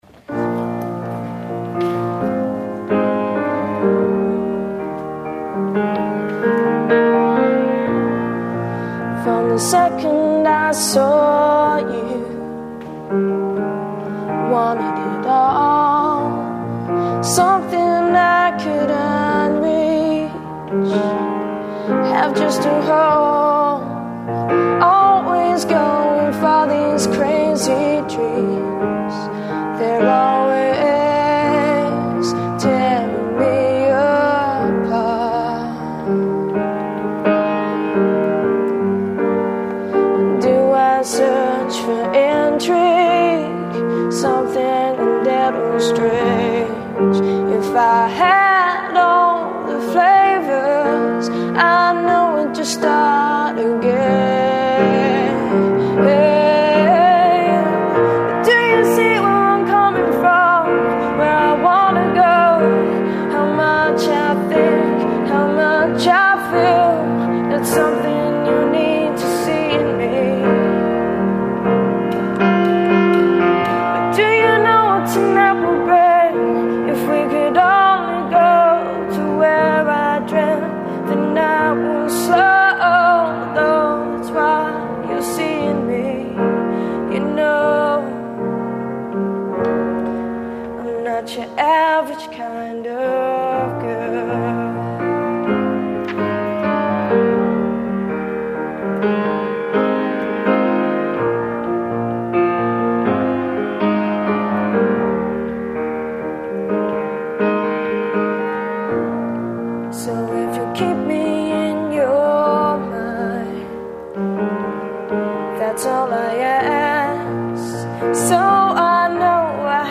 GENRE=Pop